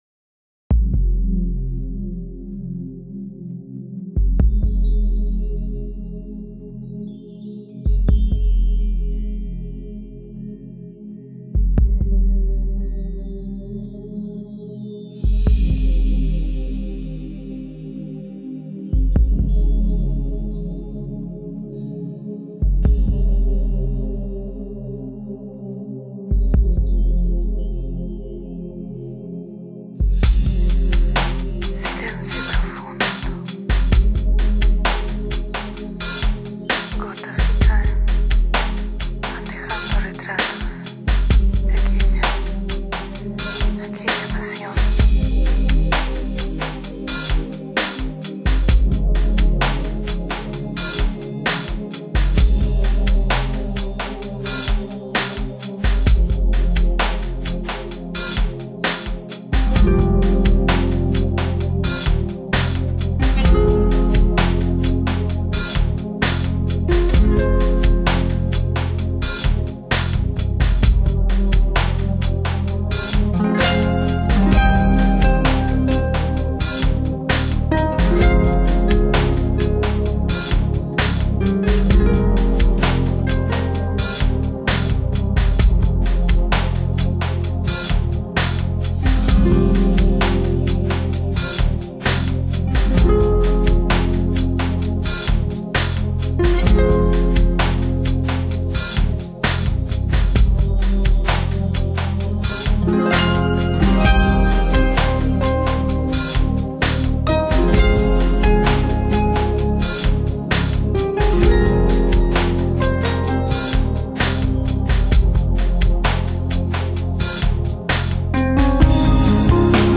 有人称她的音乐为一种突破性的实验音乐，它是以古典音乐元素，混合了多样化的现代化元素，而构成一座新浪漫古典音乐的石堡。
在这张专辑中，竖琴这种古典乐器被配上了新纪元风格的音乐背景
让原本温柔优雅的竖琴实现了令人惊讶的延伸，让我们看到竖琴音乐欢畅激扬的一面。